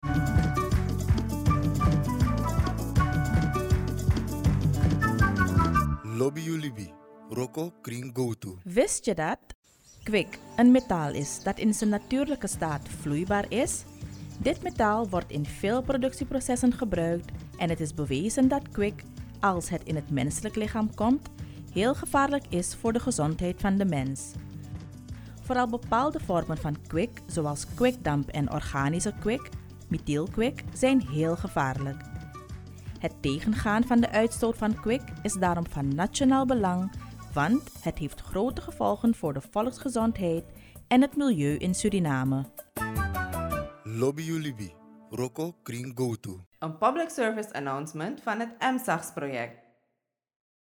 EMSAGS NL Radiospot 1